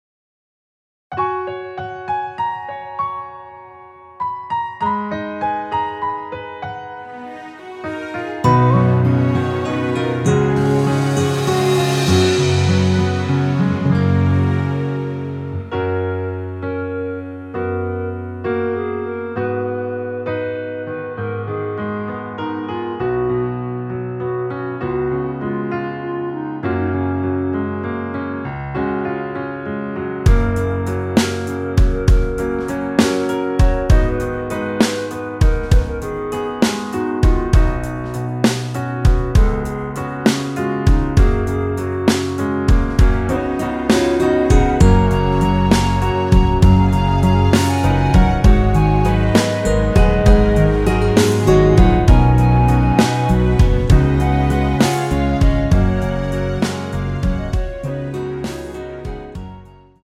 원키에서(-2)내린 멜로디 포함된 짧은 편곡 MR입니다.
F#
노래방에서 노래를 부르실때 노래 부분에 가이드 멜로디가 따라 나와서
앞부분30초, 뒷부분30초씩 편집해서 올려 드리고 있습니다.